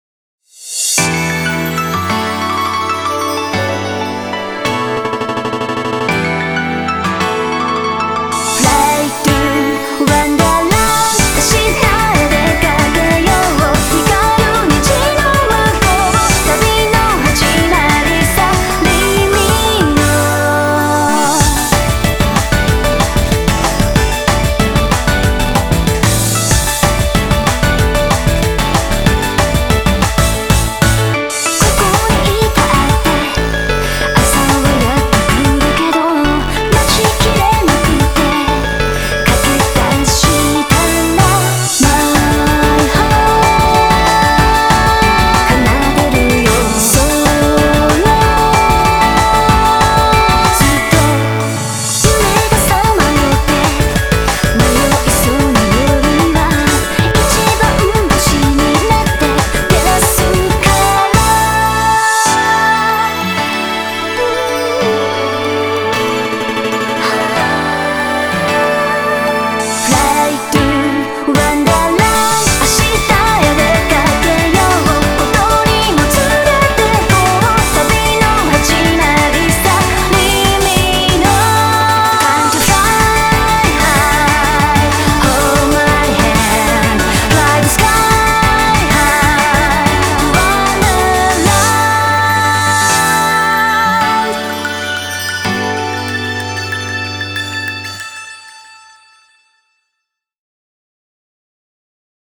BPM188
Audio QualityPerfect (High Quality)
Genre: TWINKLE POP.